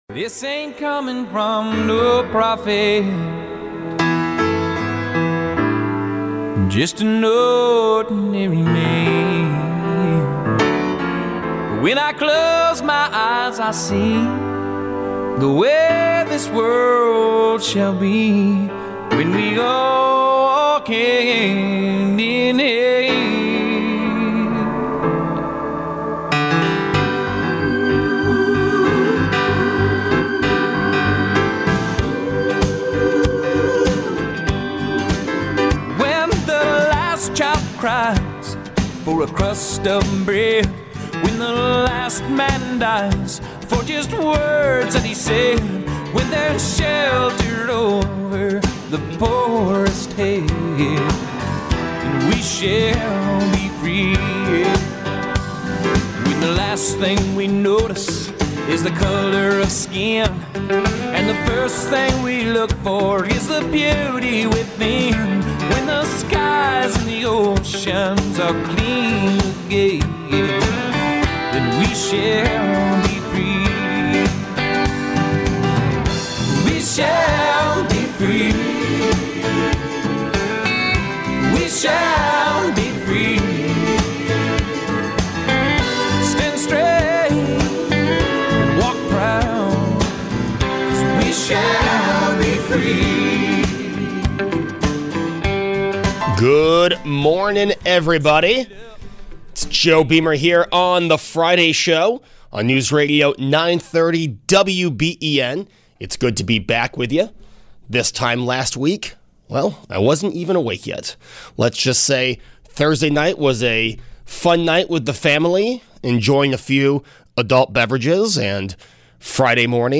Taking your calls on the Breaking News that the Diocese of Buffalo has filed for Chapter 11 Bankruptcy.